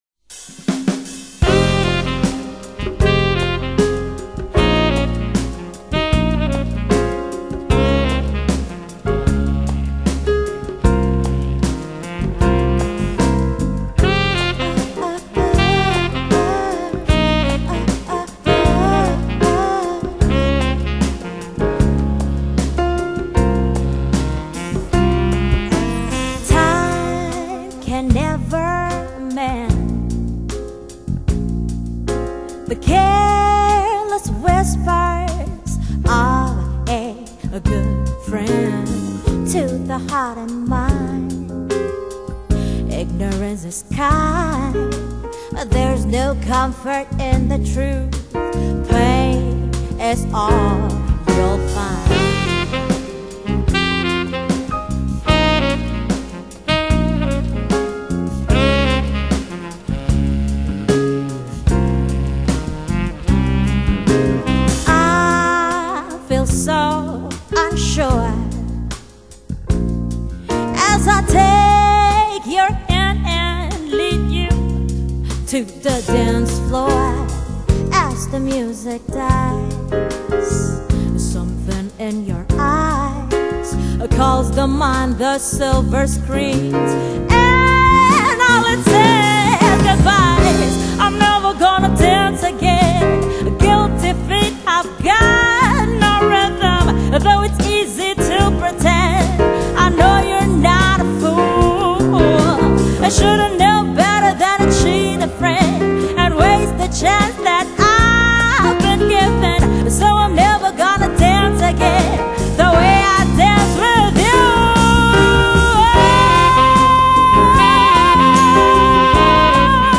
通透靚絕的音色突顯出歌手們的深情演繹，一字一句觸動人心。